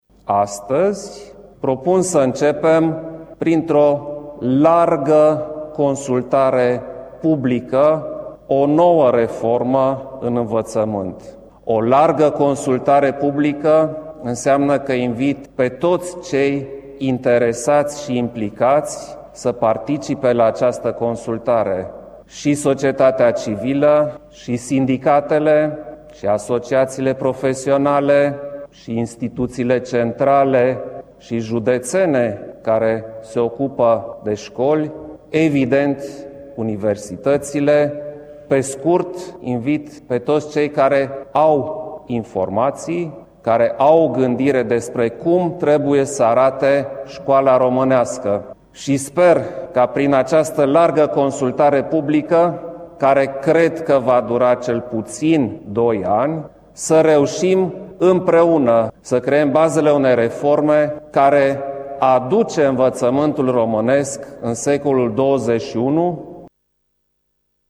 În cadrul unei conferințe de presă președintele Klaus Iohannis a criticat starea actuală din învățământ și a subliniat că e o rușine națională faptul că fiecare an școlar începe cu vești proaste, cu școli încă nerenovate și manuale lipsă.